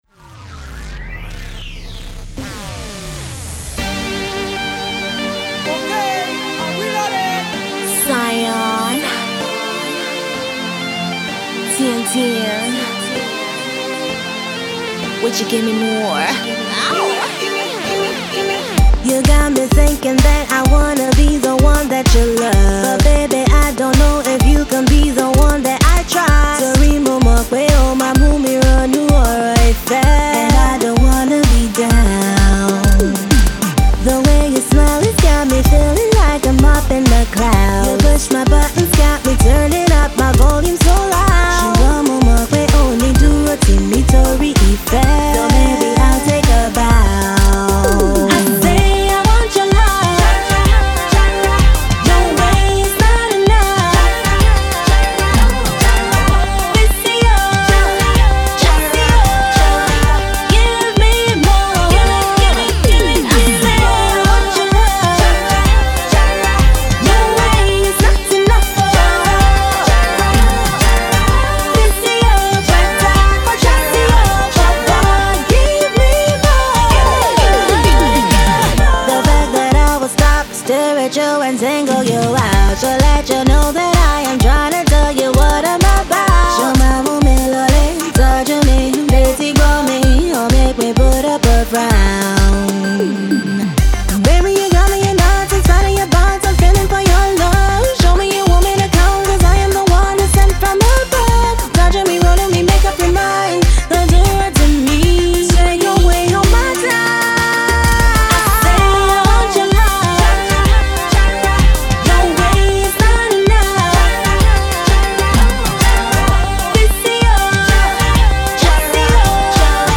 we were excited to hear that this stunning Afro-Pop starlet
Madonna-meets-Britney Spears Pop production